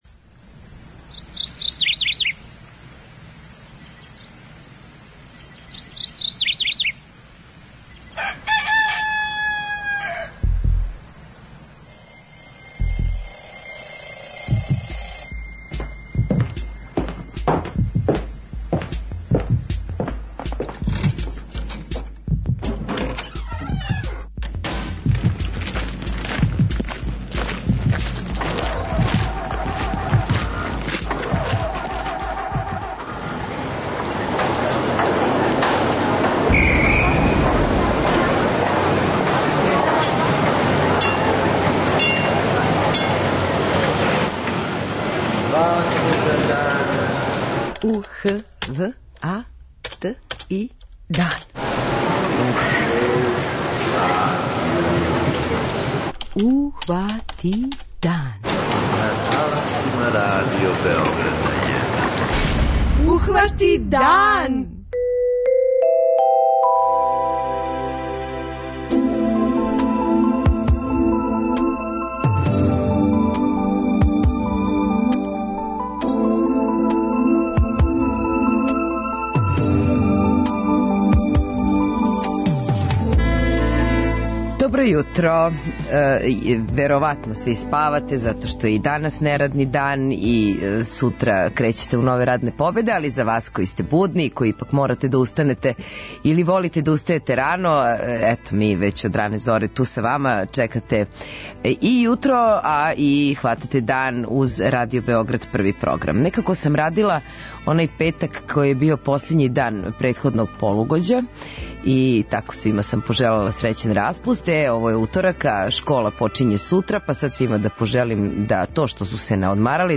преузми : 43.13 MB Ухвати дан Autor: Група аутора Јутарњи програм Радио Београда 1!